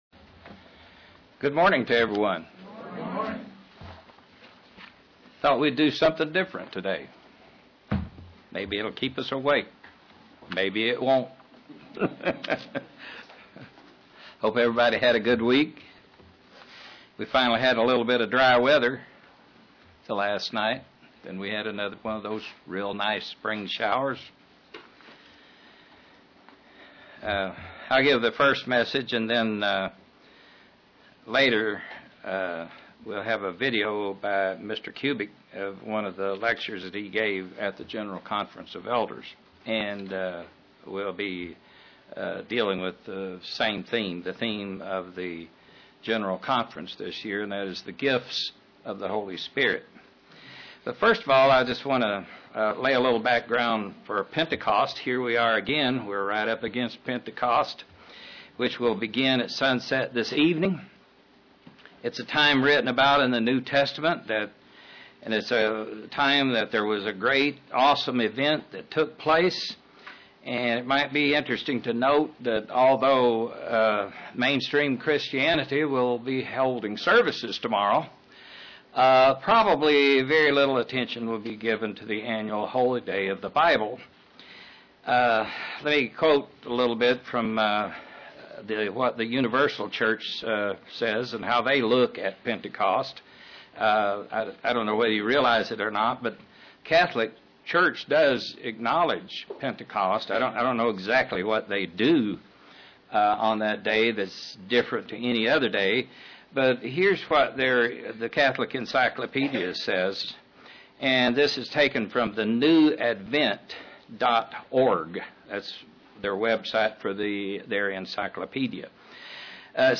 Print Basics of Pentecost UCG Sermon Studying the bible?